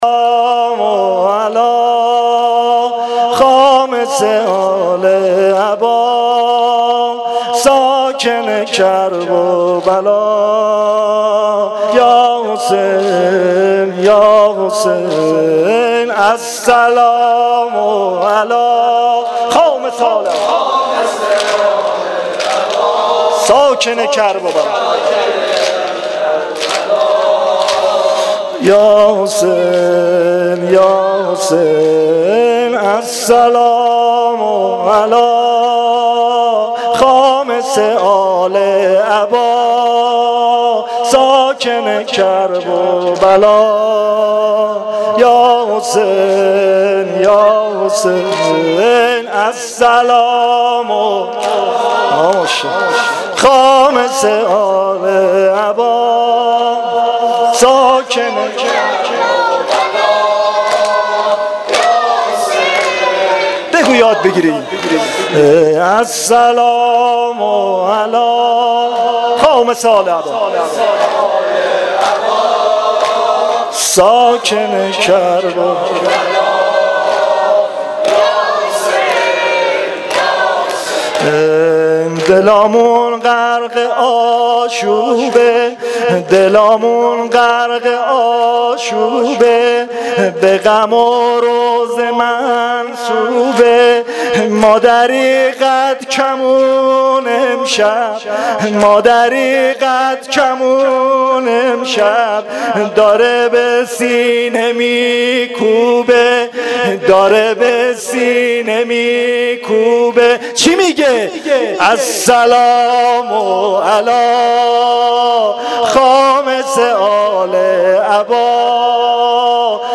زمینه شب نهم محرم